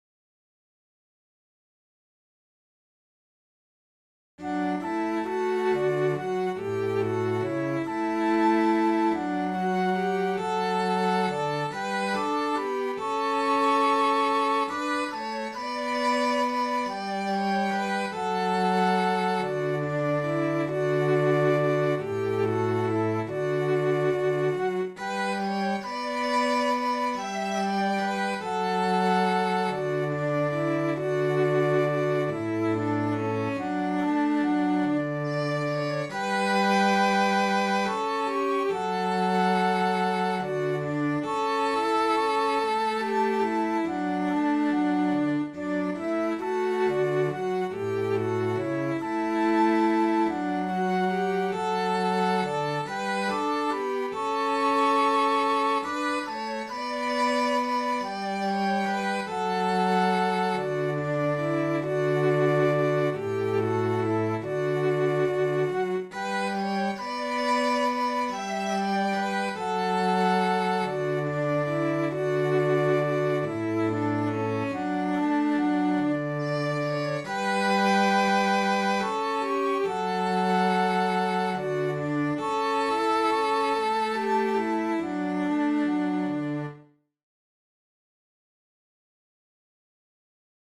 Halleluja-sellot-ja-huilu.mp3